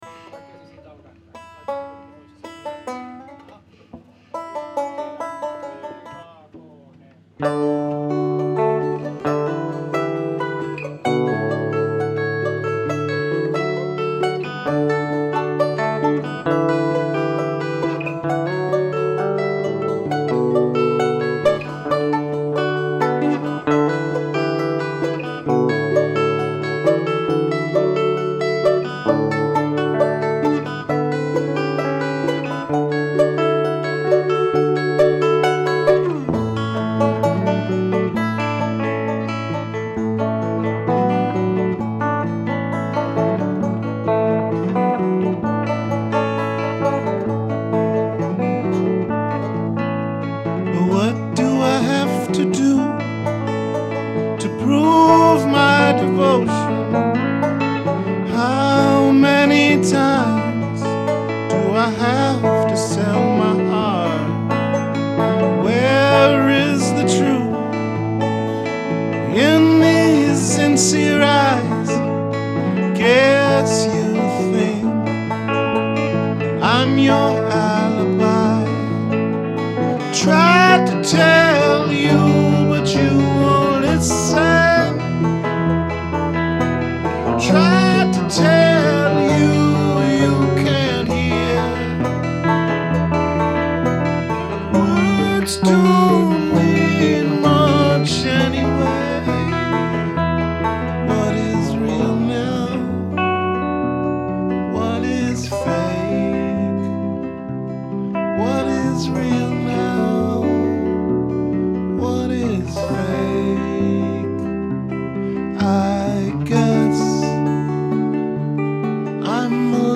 Rehearsals 25.2.2012